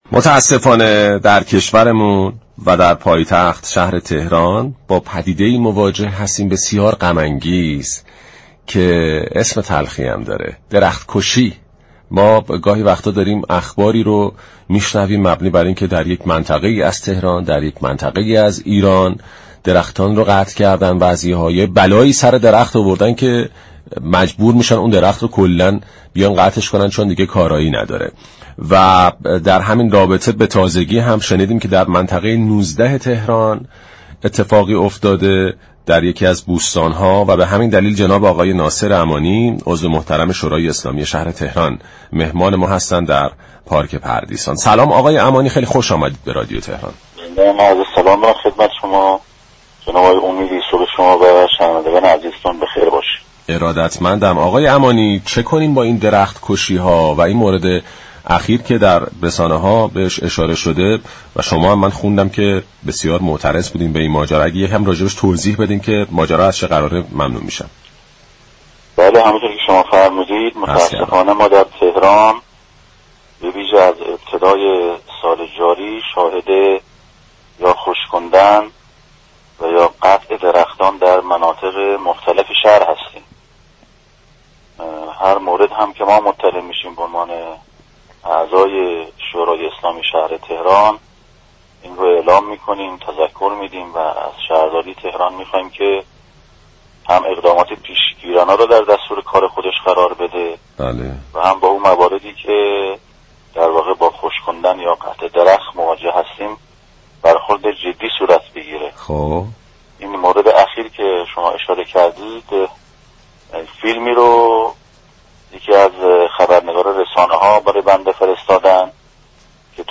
درباره این موضوع ناصر امانی عضو شورای شهر تهران به برنامه پارك پردیسان گفت: متاسفانه در تهران به ویژه از ابتدای سال جاری شاهد خشكاندن یا قطع درختان در مناطق مختلف شهر هستیم.